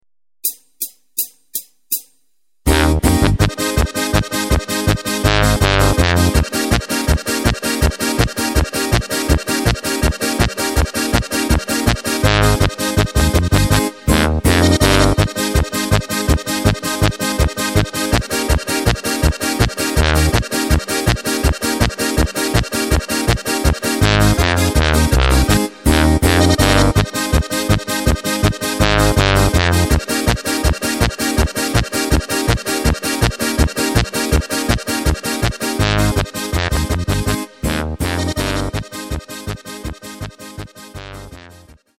(Polka)
Takt: 2/4 Tempo: 163.00 Tonart: Ab
mp3 Playback Demo